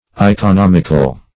Search Result for " iconomical" : The Collaborative International Dictionary of English v.0.48: Iconomical \I`co*nom"ic*al\, a. [Gr.